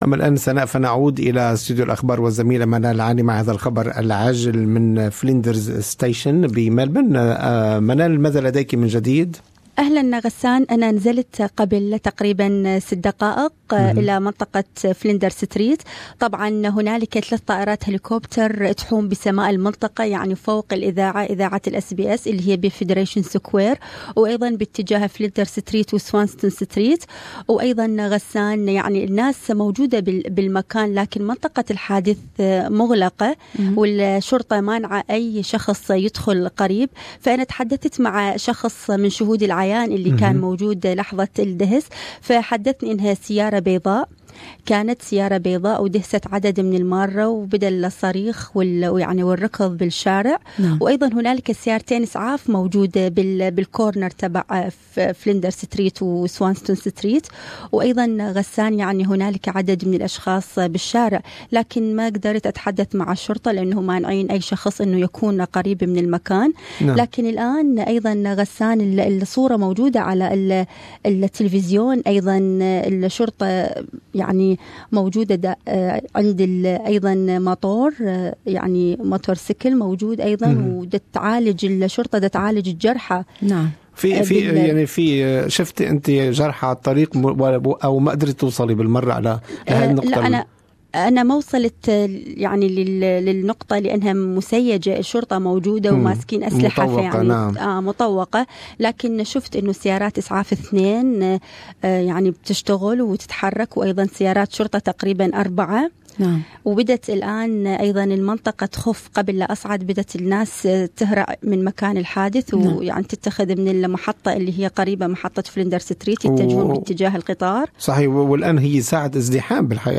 استمعوا هنا الى البث المباشر لاذاعتنا و لاذاعة BBC أيضا حمّل تطبيق أس بي أس الجديد للإستماع لبرامجكم المفضلة باللغة العربية .